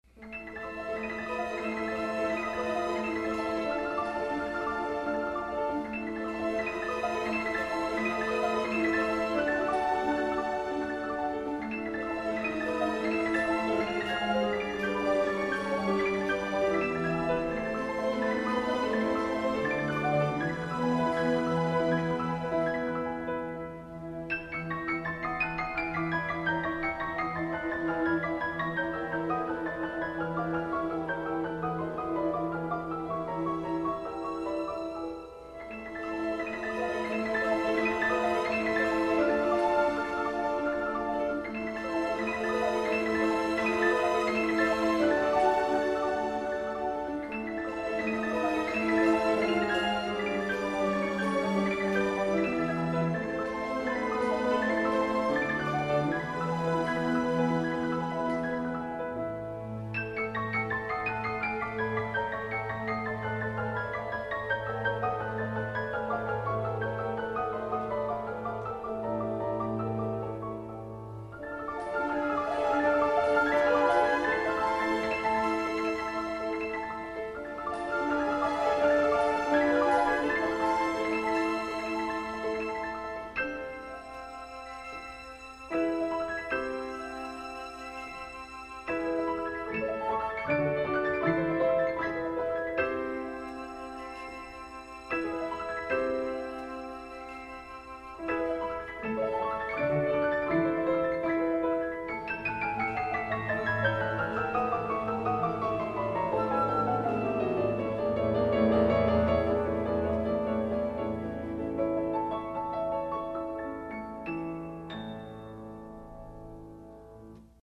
Звук волшебной мелодии для появления Золотой рыбки на сцену